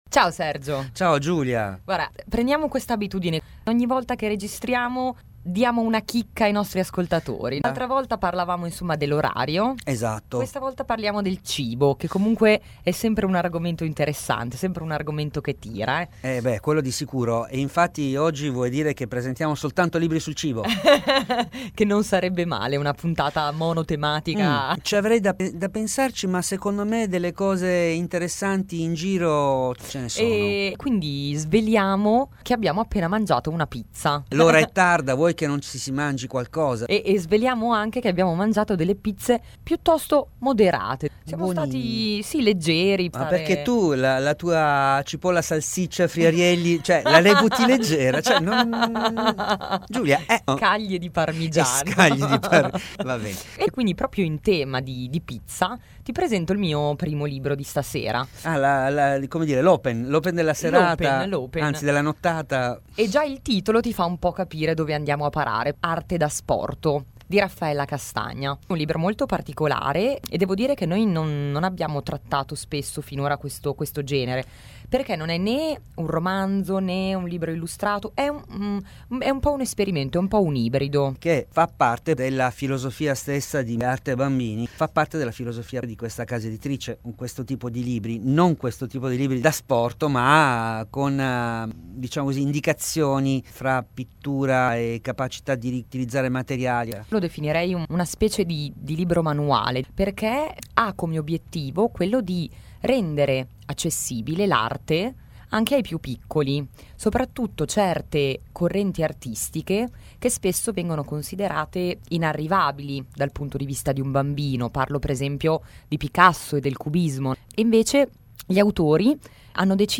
Intervista all’autrice nel podcast.